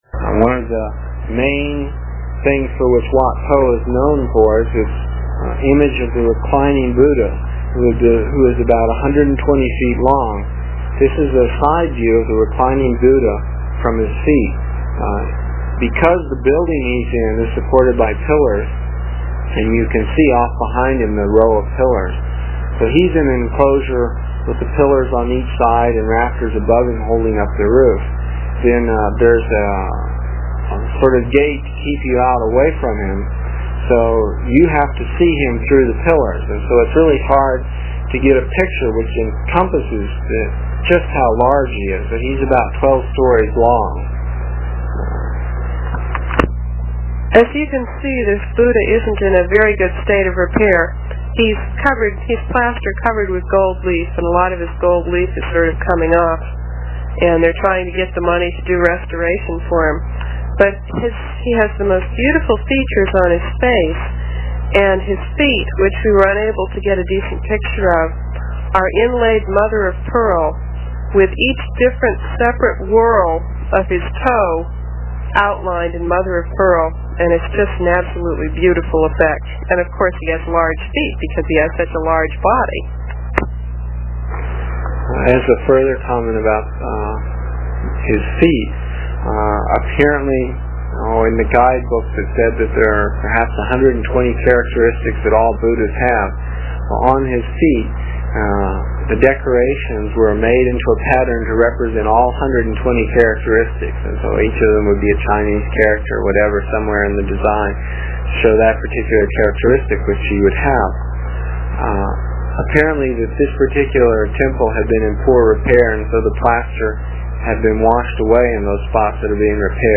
It is from the cassette tapes we made almost thirty years ago. I was pretty long winded (no rehearsals or editting and tapes were cheap) and the section for this page is about nine minutes and will take about three minutes to download with a dial up connection.